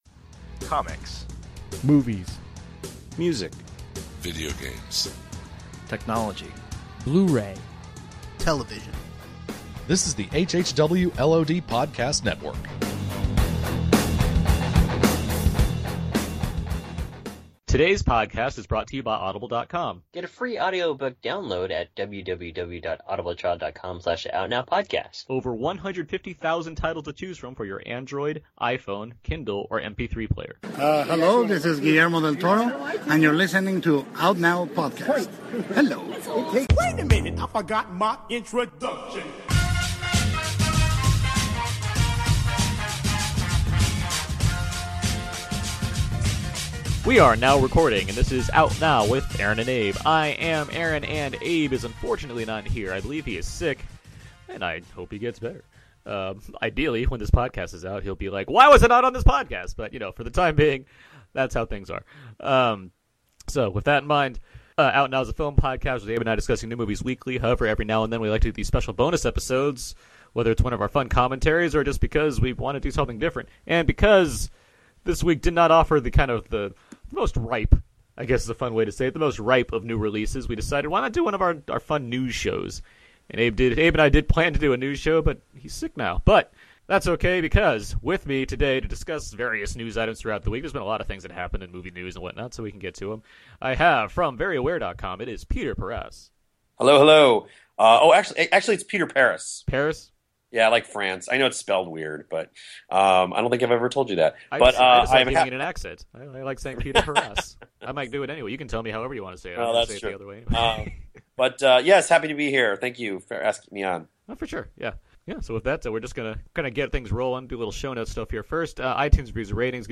just a two-hander